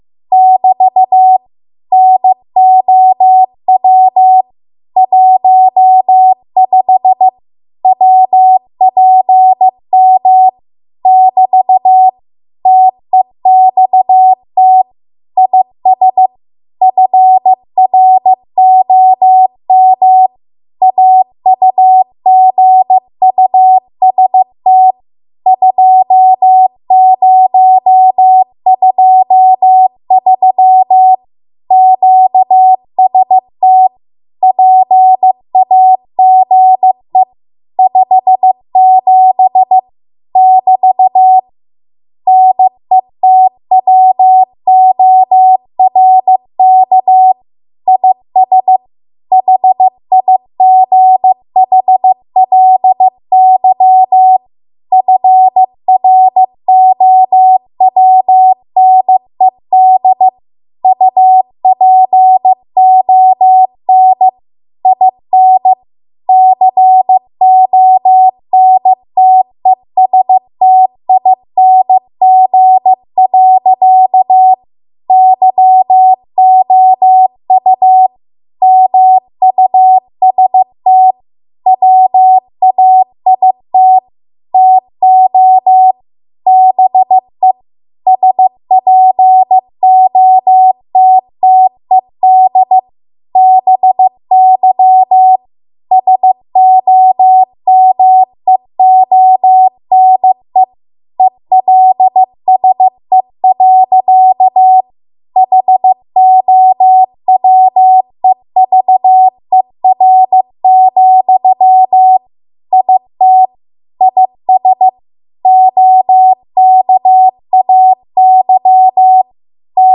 15 WPM Code Practice Archive Files
Listed here are archived 15 WPM W1AW code practice transmissions for the dates and speeds indicated.
You will hear these characters as regular Morse code prosigns or abbreviations.